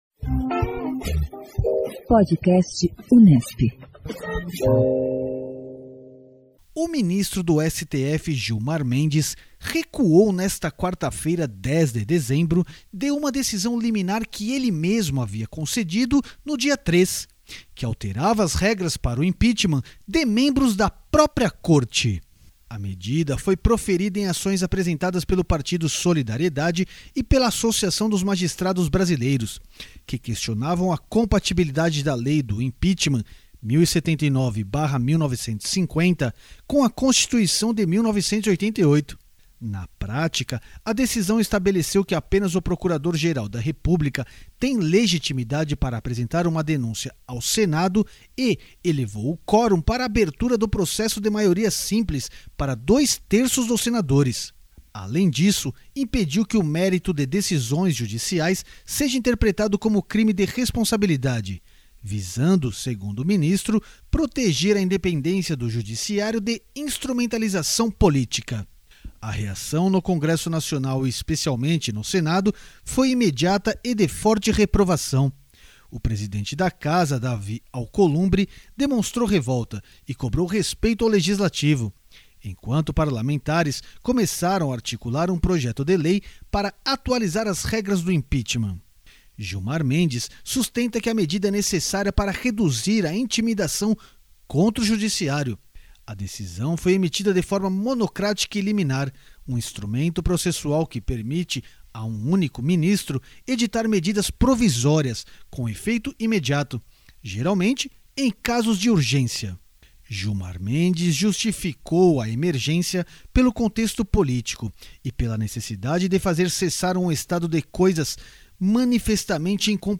O Podcast Unesp / Assessoria de Comunicação e Imprensa da Reitoria da Unesp traz entrevistas com professores, pesquisadores e alunos sobre pautas cotidianas da mídia brasileira, internacional e informações geradas na Universidade.